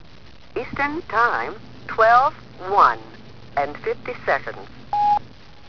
Registrazioni sonore di happening Fluxus